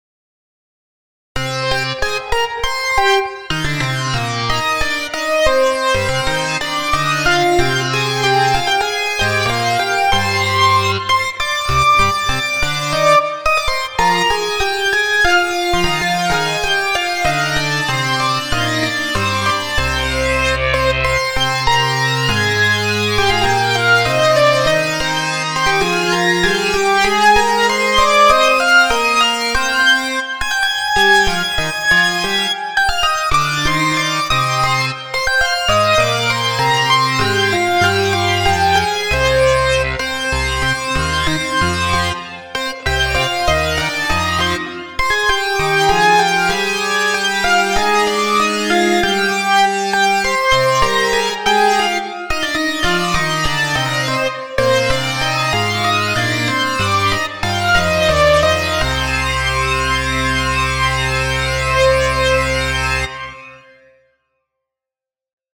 So kann man z. B. auch den Clavinet-Sound eines Softwaresynths damit gut spielen, wie in folgendem Beispiel: prophet5_1